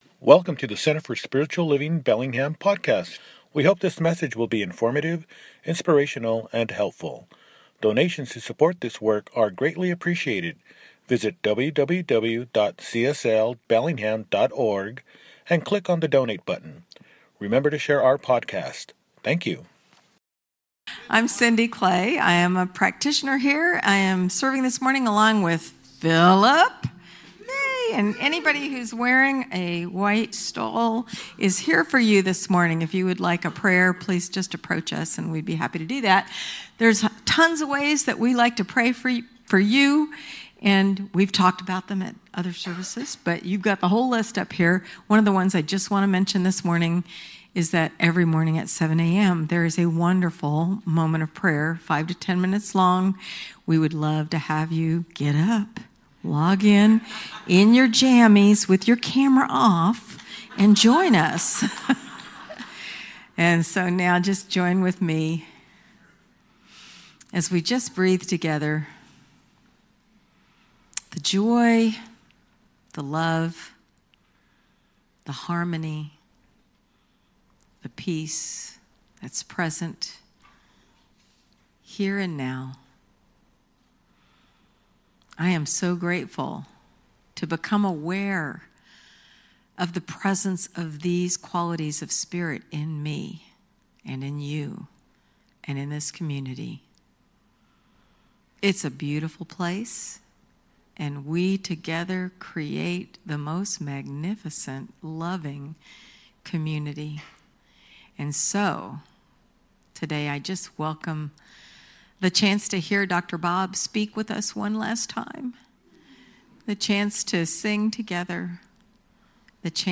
It’s Our Time Now – Celebration Service
Jul 13, 2025 | Podcasts, Services